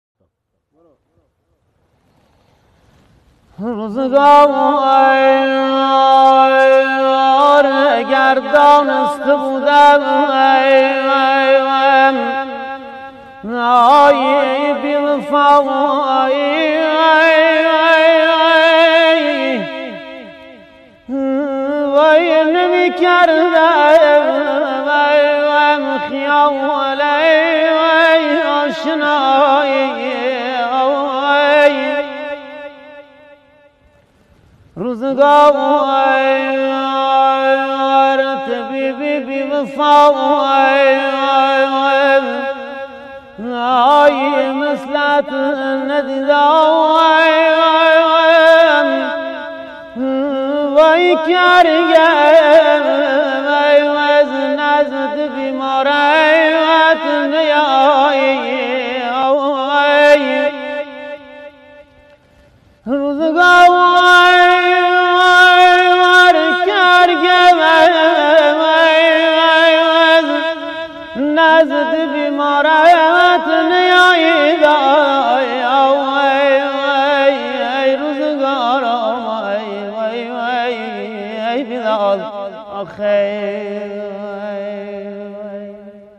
آهنگ دشتی غمگین